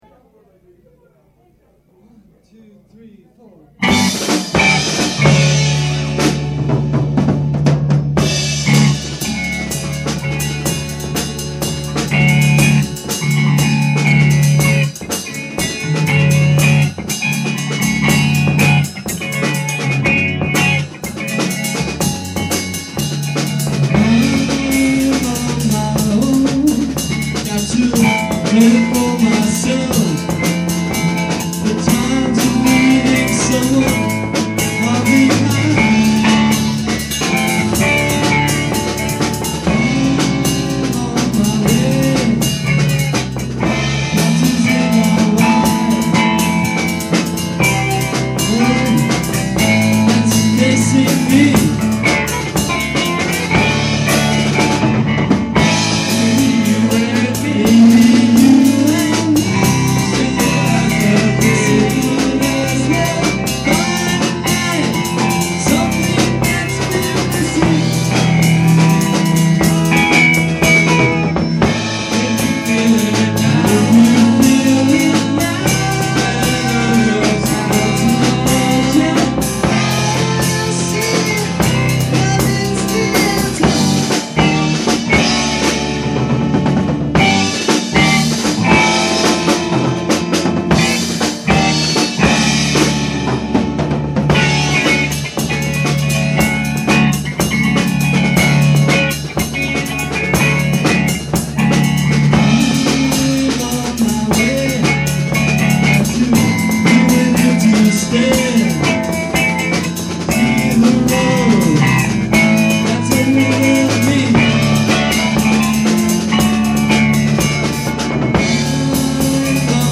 percussion
keyboards
guitar, vocals